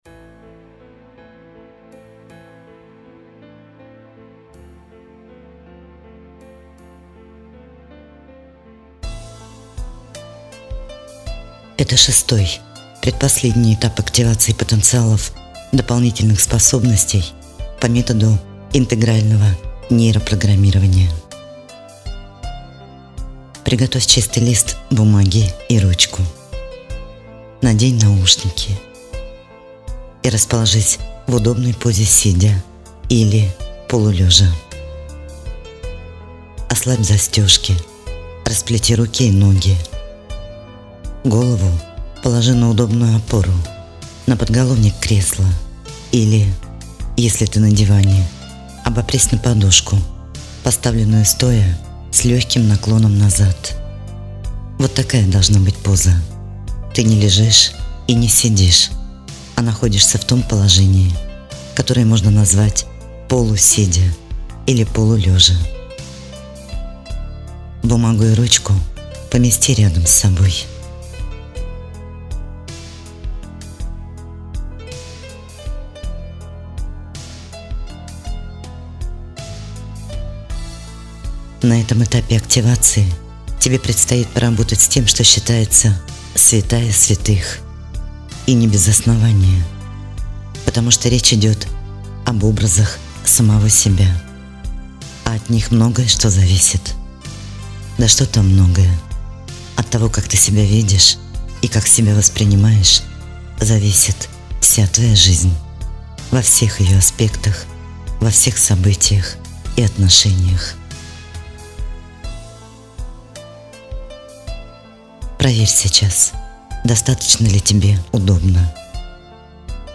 Формат 6-го этапа активации – аудиосеанс.